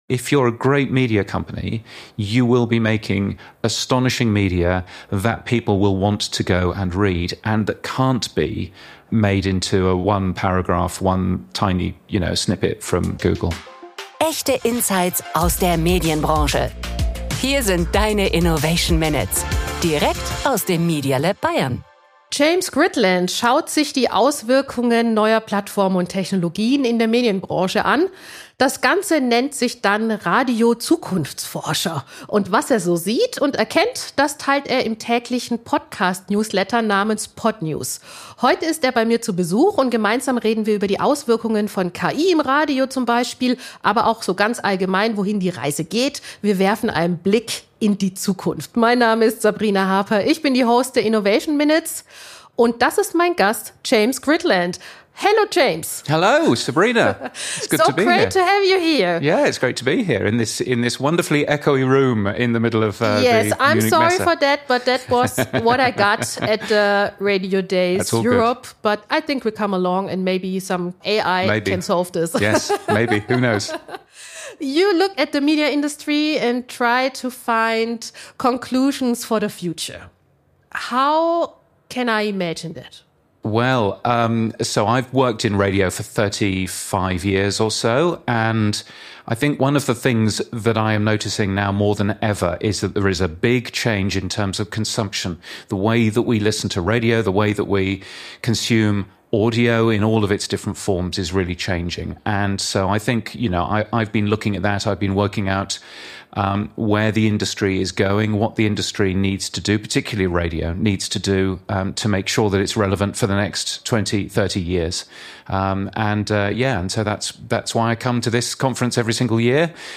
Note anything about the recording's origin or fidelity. The podcast was recorded at Radiodays Europe 2024.